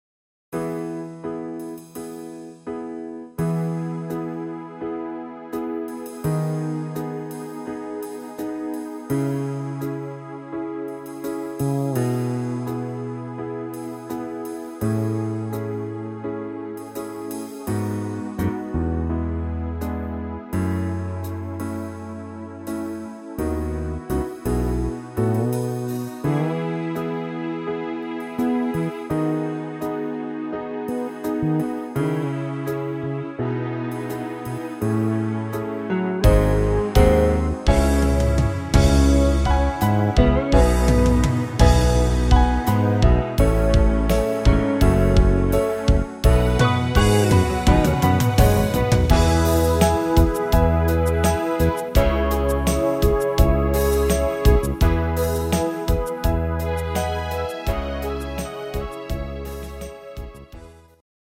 Buy -- Playback abmischen Buy